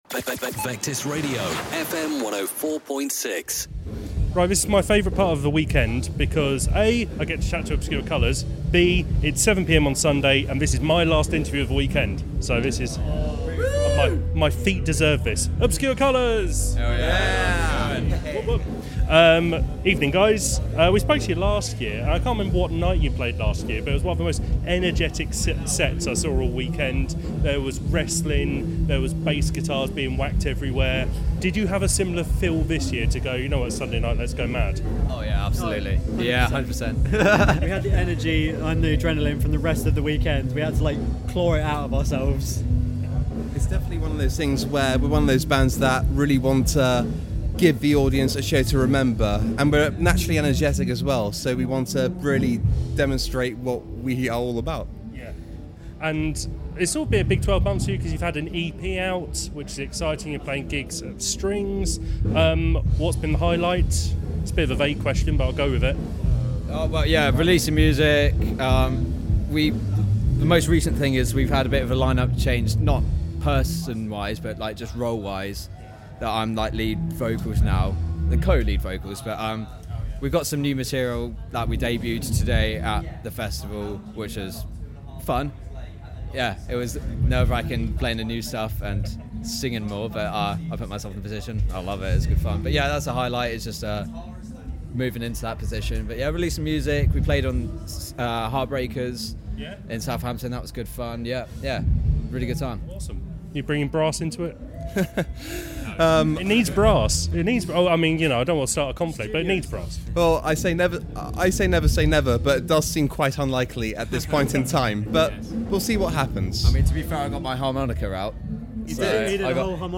Isle of Wight Festival 2024 - Obscure Colours return to chat about band role dynamics and (not) adding brass to their sound